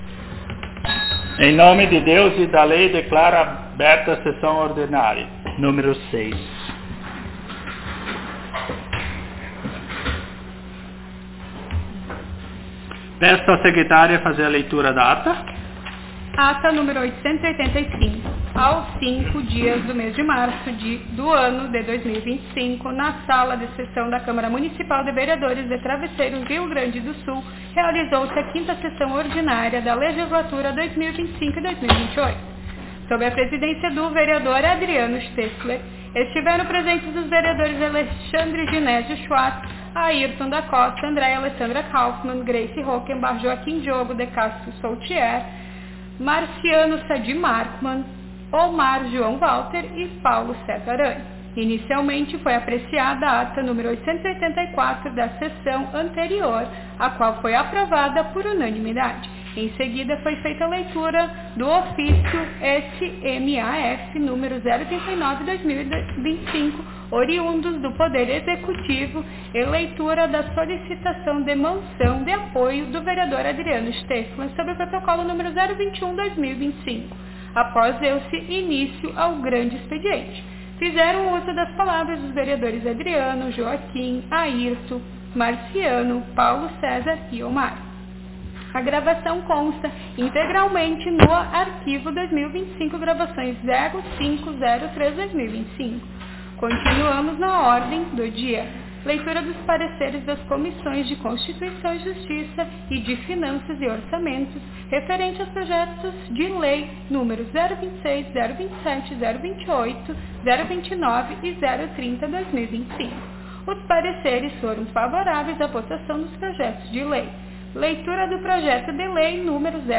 SESSÃO ORDINÁRIA Nº 06 - Legislatura 2025-2028
Aos 17 (dezessete) dias do mês de março do ano de 2025 (dois mil e vinte e cinco), na Sala de Sessões da Câmara Municipal de Vereadores de Travesseiro/RS, realizou-se a Sexta Sessão Ordinária da Legislatura 2025-2028.